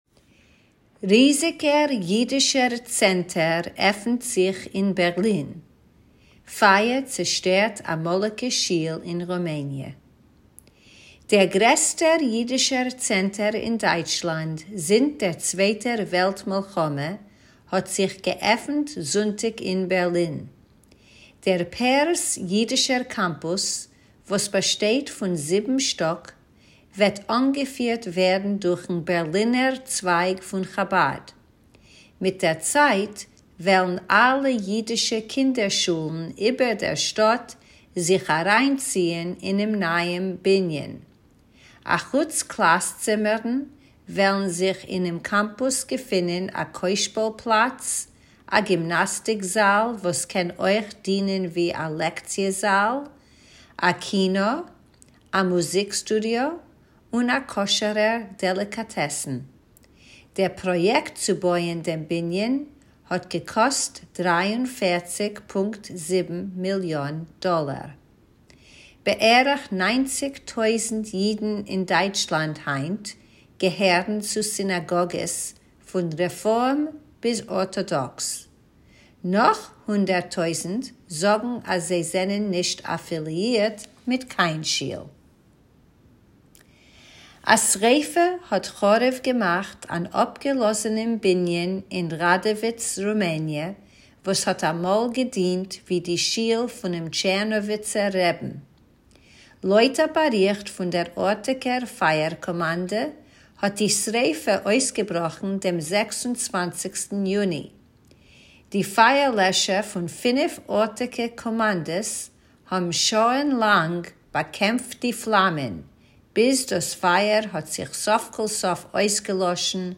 Tidbits is a weekly feature of easy news briefs in Yiddish that you can listen to or read, or both!